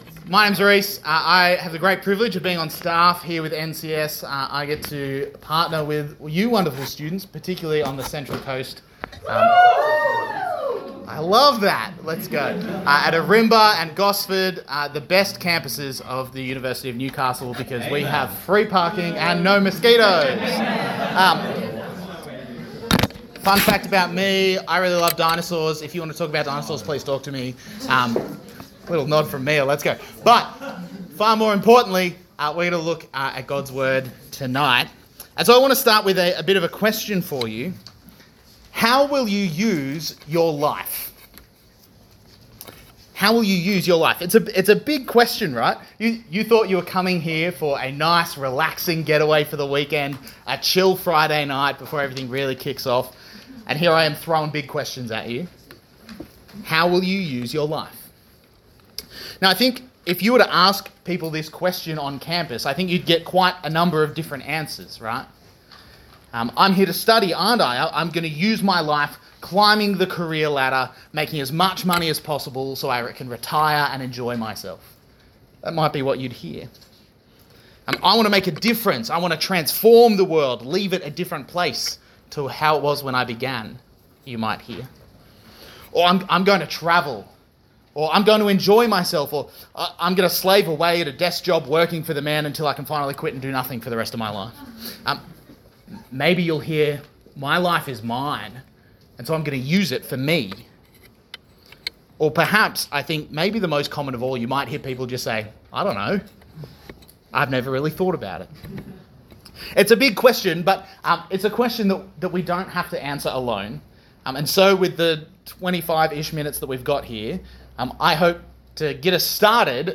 Talk Type: Getaway Topics: ministry , mission